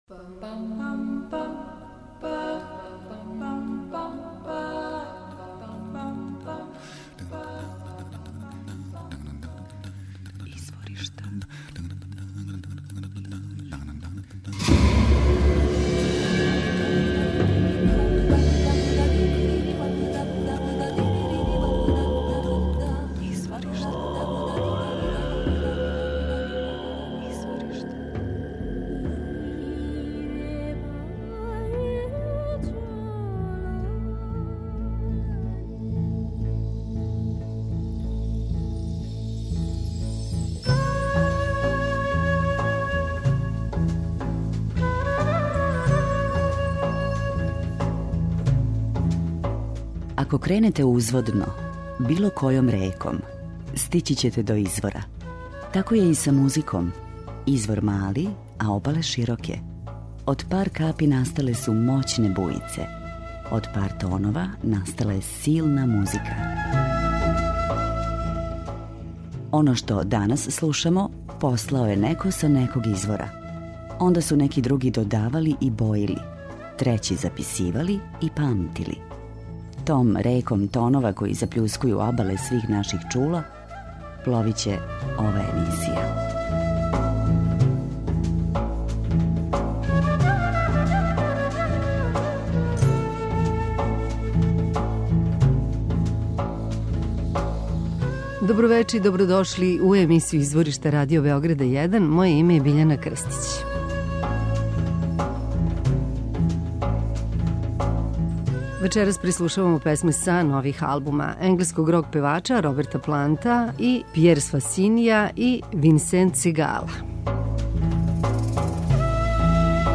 енглеског рок певача
са наглашеним ритмовима и суптилним мелодијама